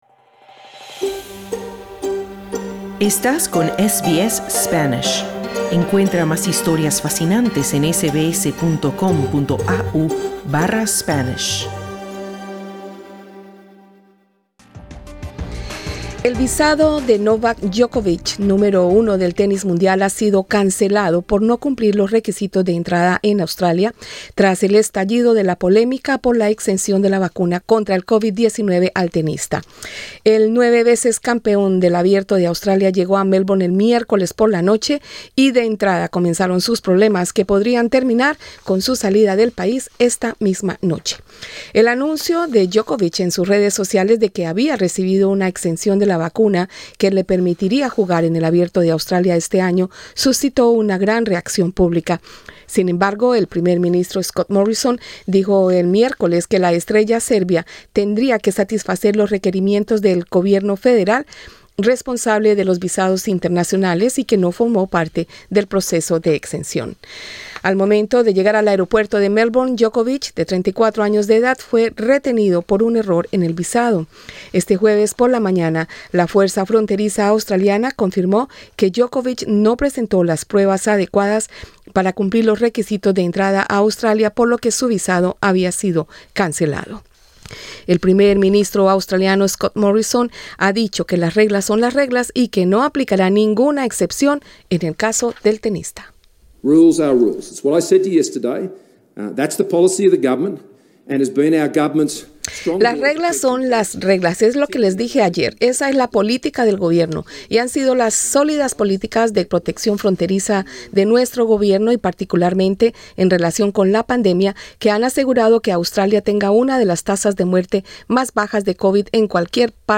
Las esperanzas de Novak Djokovic de ganar su décimo Open de Australia y el histórico récord de 21 Grand Slams se hicieron pedazos cuando su visa fue cancelada al llegar a Melbourne. Pero su ausencia también repercute al primer gran torneo de la temporada. Entrevista con el periodista experto en temas de tenis